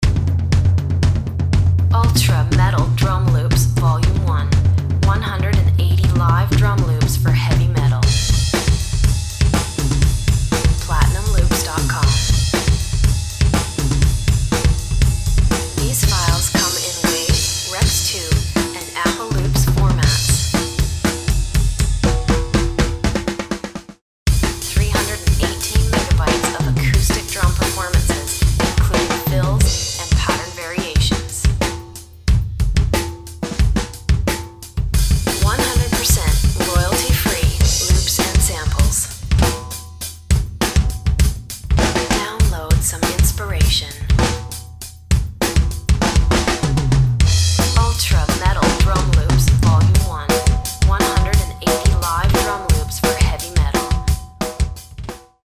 Dry and punchy metal drum loops and fills, ready to drop straight into your rock and metal tracks.
These intense metal drum loops were recorded live at our newly constructed studio deep in the rain forests of British Columbia. We used a brand new Premiere APK Cabria Drum Kit with Sabian and Zildjian cymbals and a Pearl double kick pedal.
Our unfinished live room gave us a sparkling ambience that really adds to the powerful energy of our resident metal drummer who gave the new Remo drum heads some serious punishment. The kick drum gives you the right amount of “click” to cut through heavy guitar riffs and the snare drum is crisp and punchy.
This loop pack offers a wealth of modern metal grooves, double pedal licks and thunderous fills which should add some real aggression and power to your metal or rock productions.
Tempos range from 90 bpm to 175 bpm.